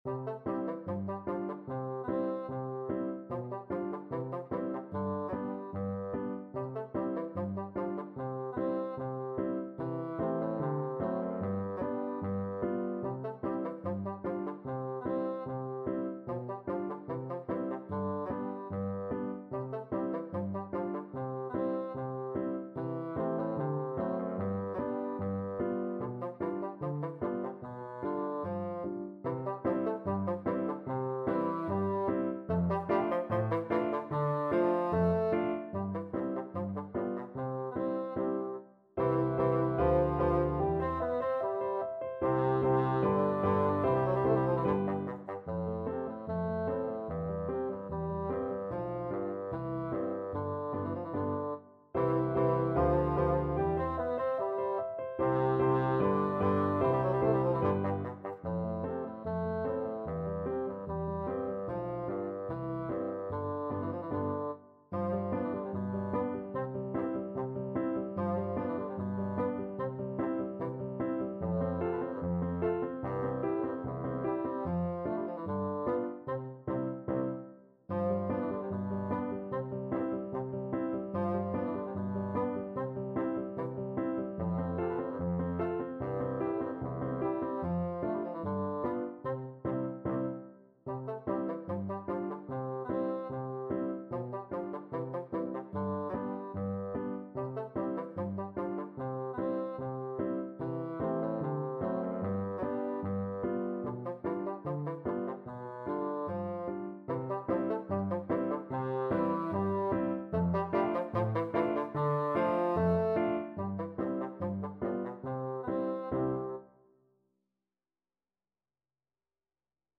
Free Sheet music for Bassoon
2/2 (View more 2/2 Music)
Allegretto = 74
Classical (View more Classical Bassoon Music)